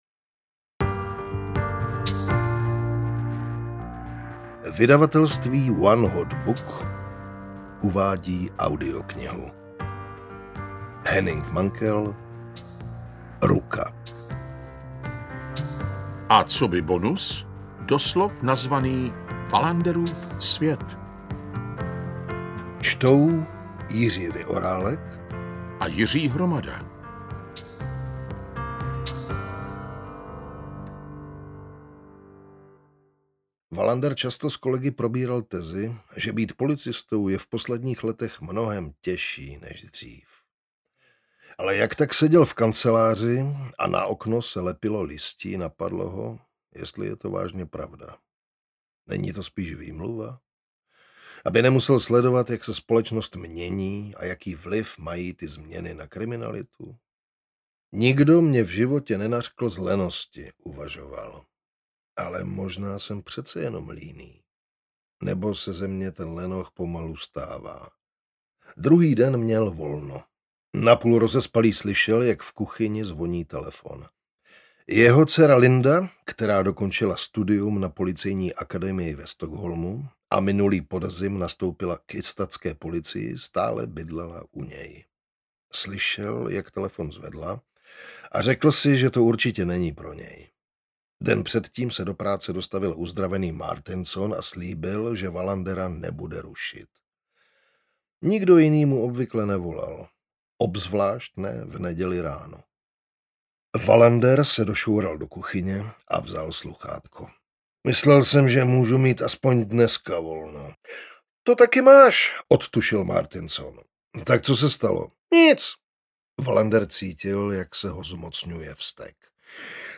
Ruka / Wallanderův svět audiokniha
Ukázka z knihy
• InterpretJiří Vyorálek, Jiří Hromada